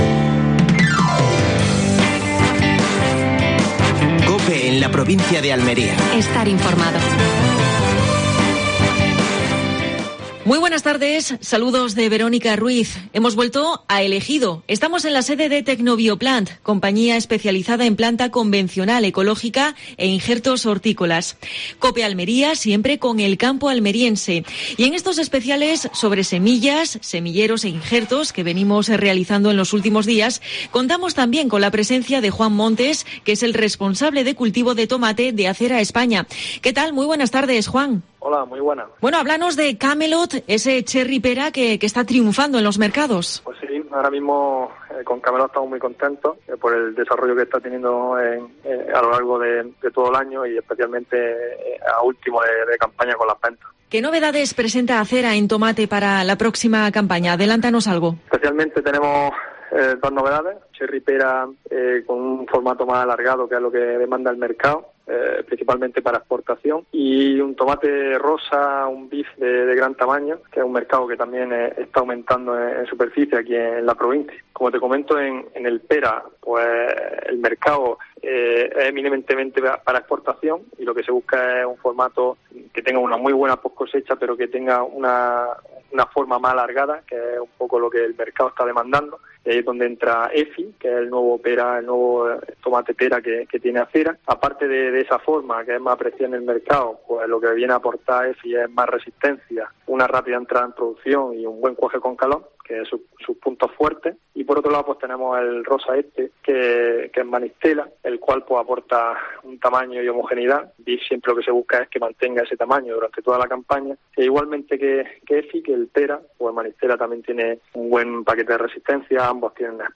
Última hora en Almería. Previsión del tiempo. Estado de las carreteras y de la mar. Entrevista